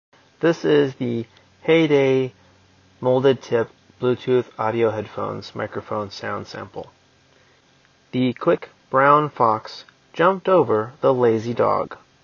As we are fairly well aware, small mics don’t sound great.
This mic is further hampered by the fact that physical movement is pretty readily captured as the joining wire drags across your hair or shirt. The placement of the mic and the controls around it lead to a basic sound that is pretty good.
Audio sample of the headphones connected to an iPhone 7:
hayday-mic-test-normalized.m4a